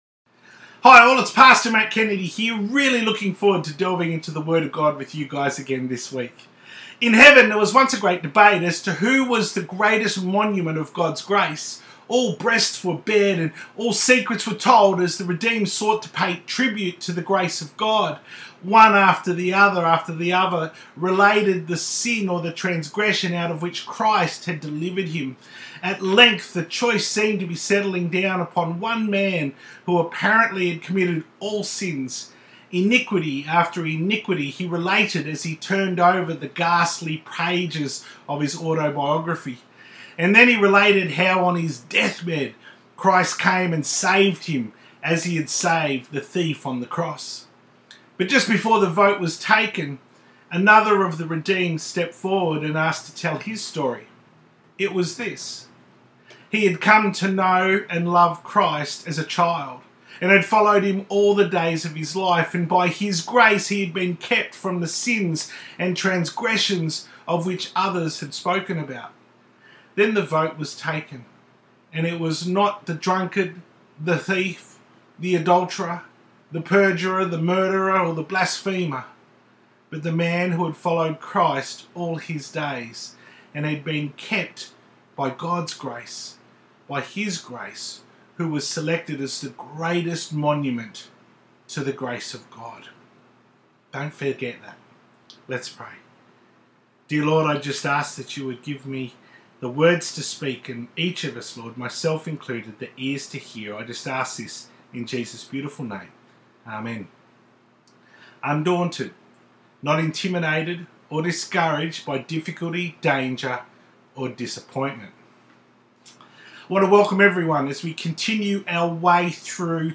To view the Full Service from 29th November 2020 on YouTube, click here.